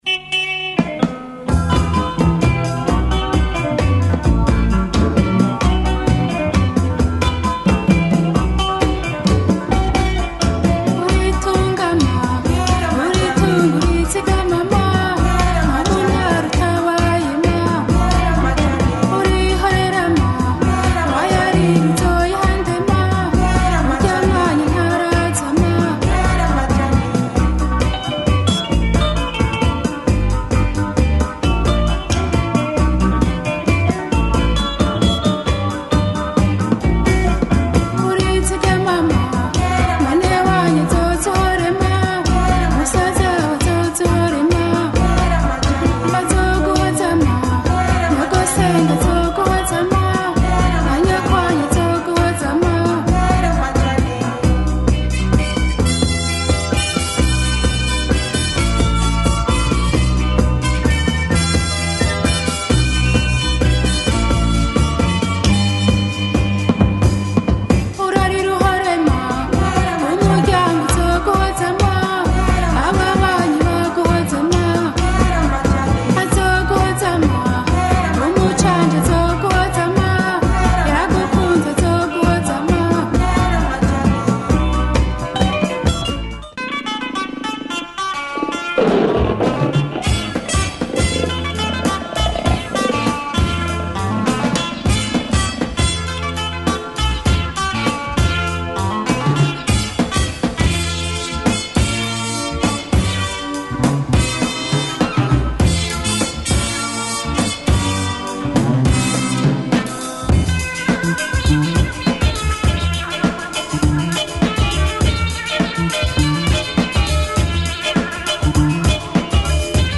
Burundian band